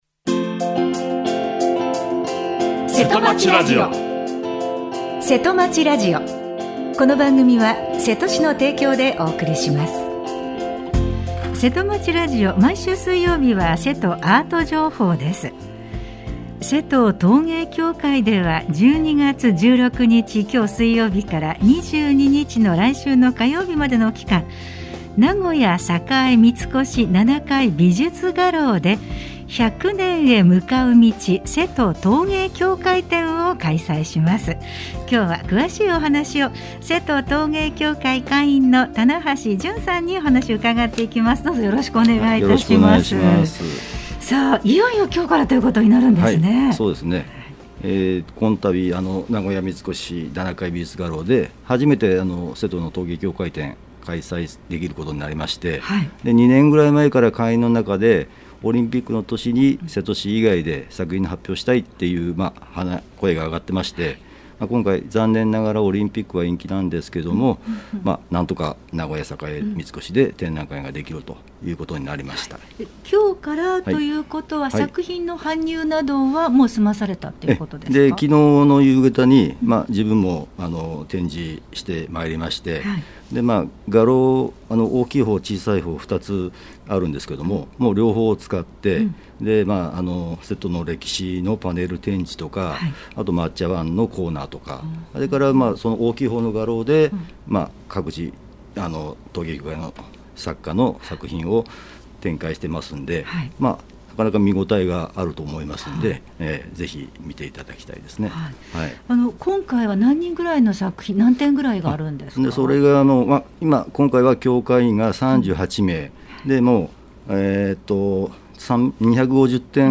今回は、現場リポートです。